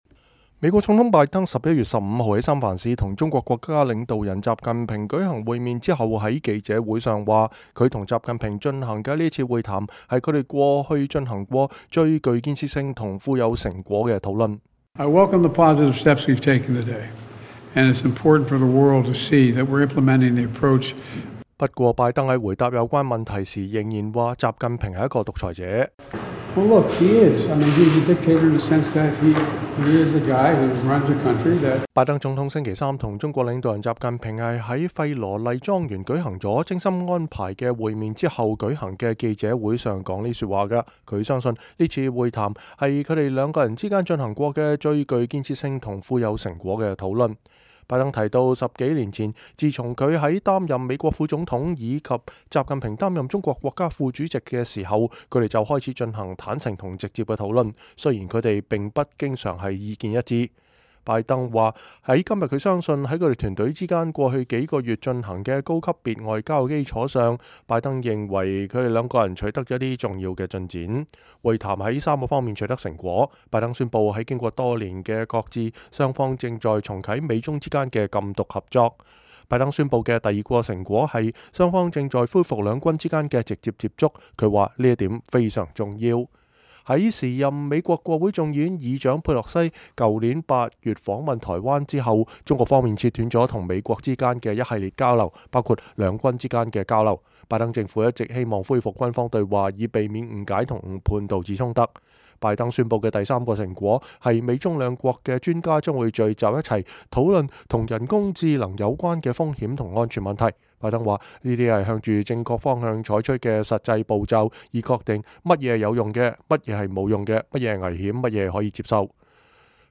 美國總統拜登11月15日在舊金山與中國國家領導人習近平舉行了會晤之後在記者會上表示，他與習近平進行的這次會晤是他們進行過的“最具建設性和富有成果的討論” 。 不過他在回答有關問題時仍然說習近平是”獨裁者“。